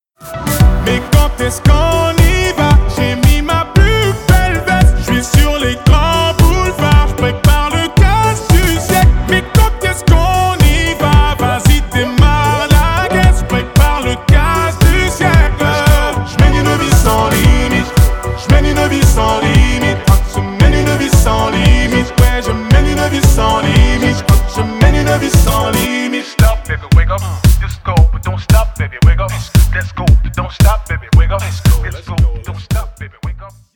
• Качество: 320 kbps, Stereo
Танцевальные
клубные